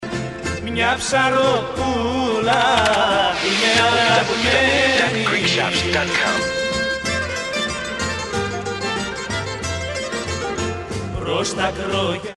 non-stop Greek folk dance songs